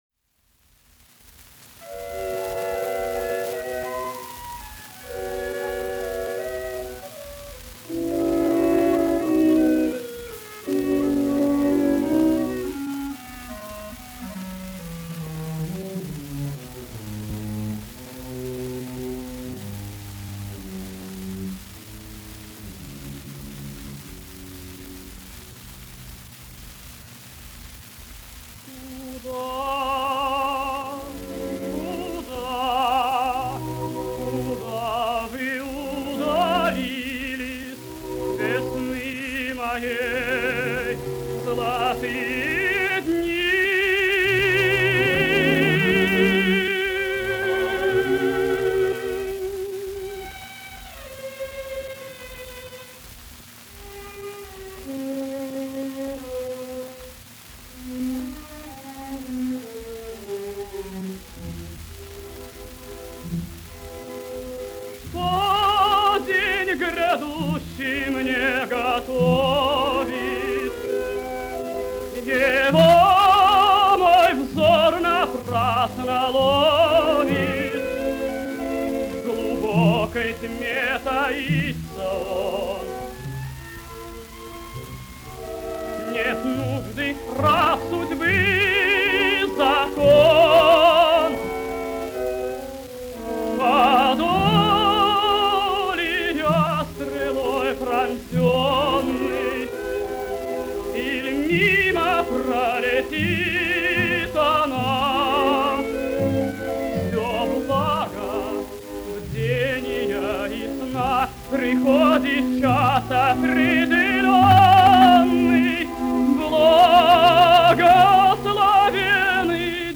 Арии из опер.